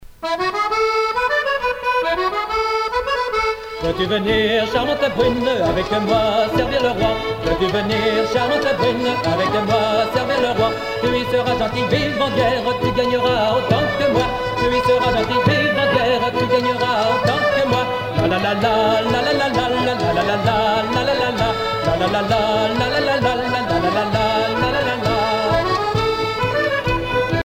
danse : avant-quatre
Pièce musicale éditée